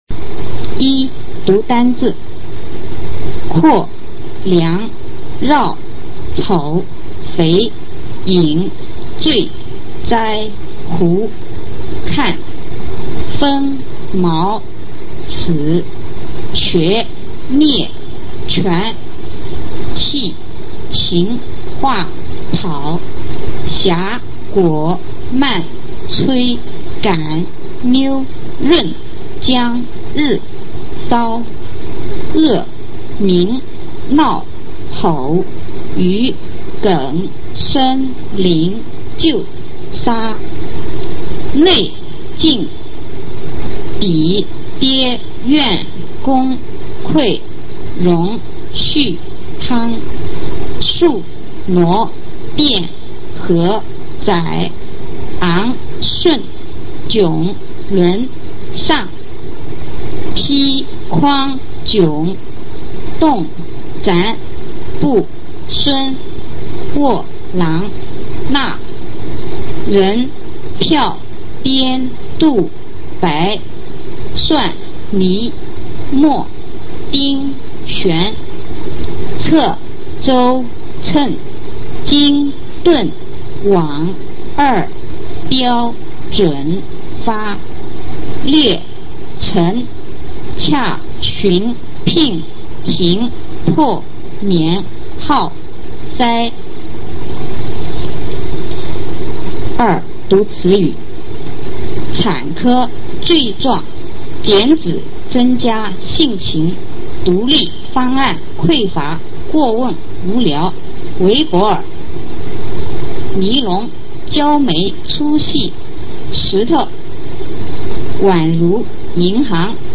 首页 视听 学说普通话 等级示范音频
普通话水平测试三级乙等示范读音